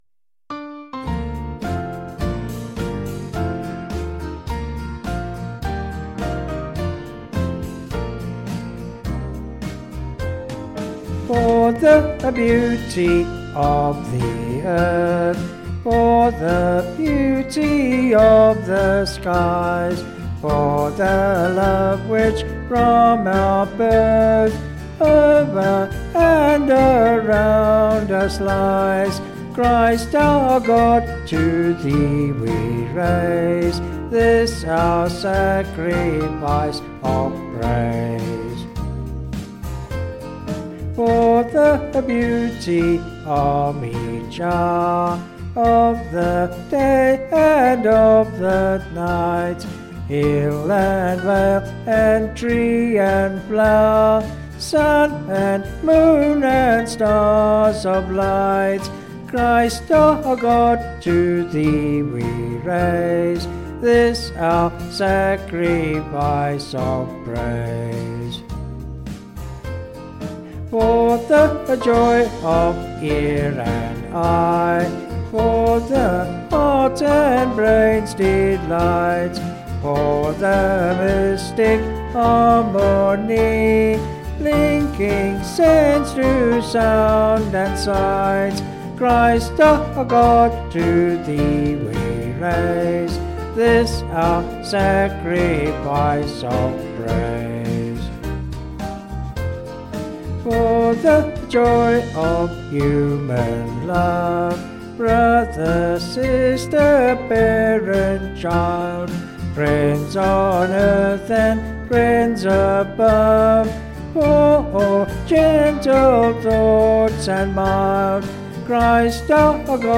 (BH)   5/Bb
Vocals and Band   265.1kb Sung Lyrics